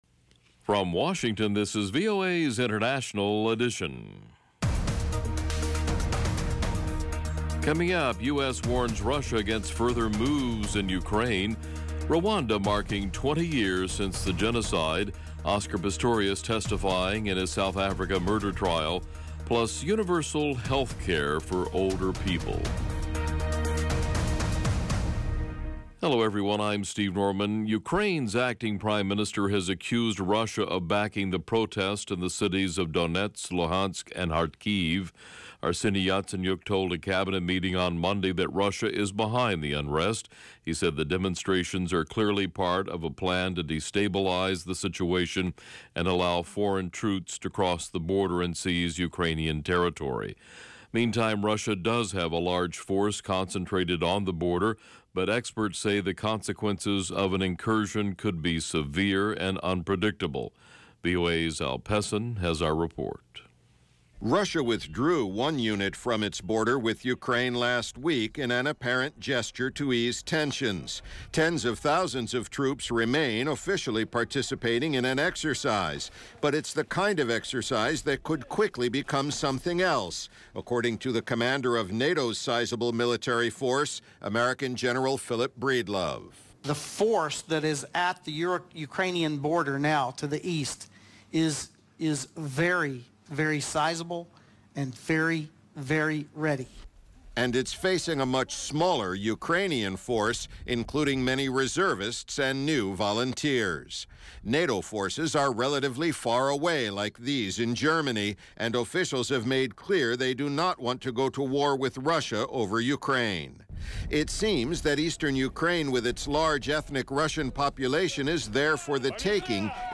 International Edition gives you 30 minutes of in-depth world news reported by VOA’s worldwide corps of correspondents - on the events people are talking about.